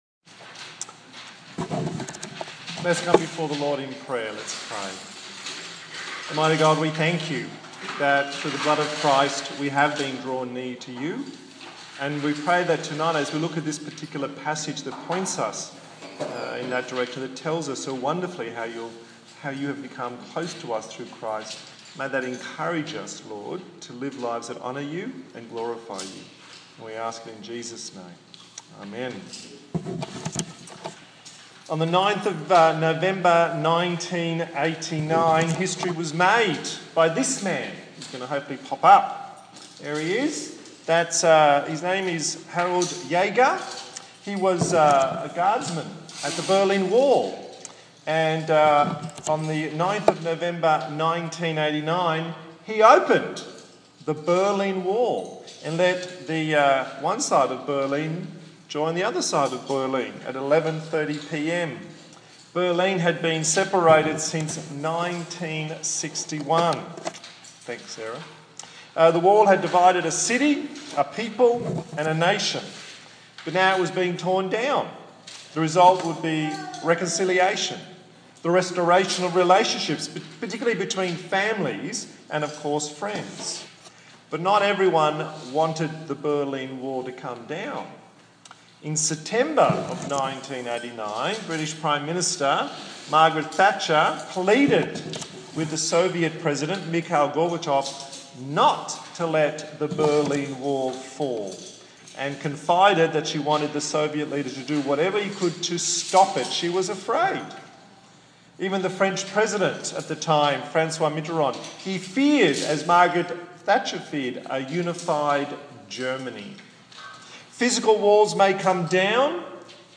25/10/2015 The New Humanity Preacher